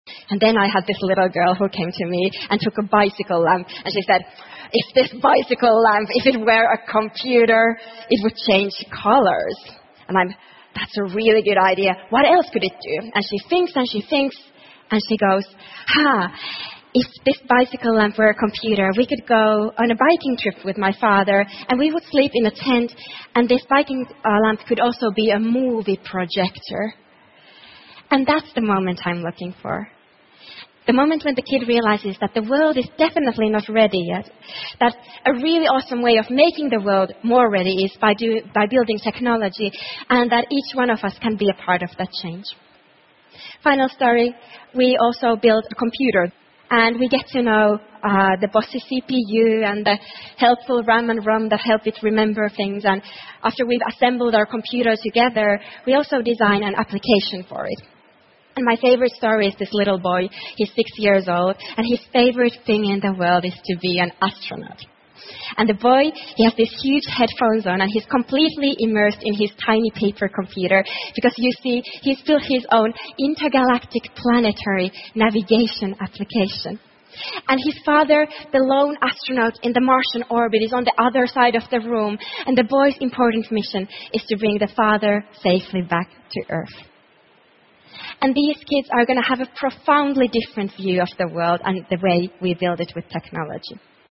TED演讲:编织我的梦() 听力文件下载—在线英语听力室